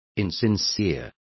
Complete with pronunciation of the translation of insincere.